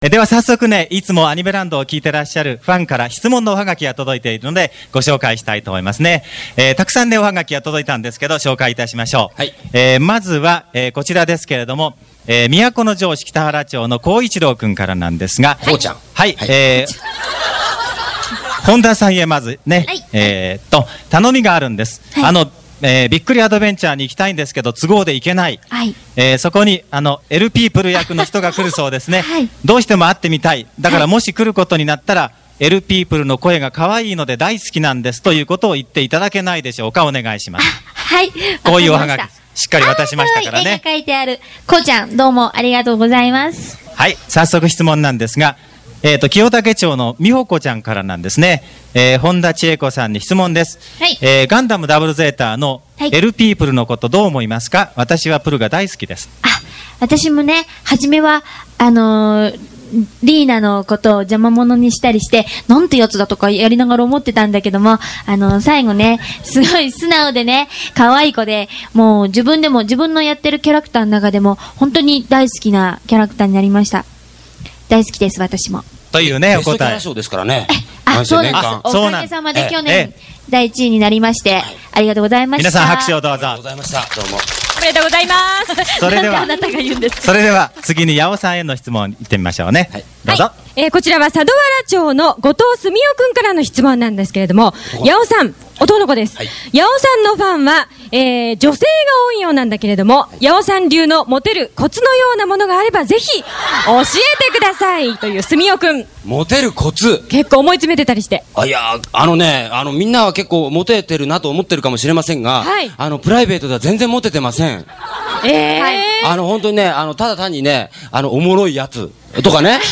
夏のえびの高原です。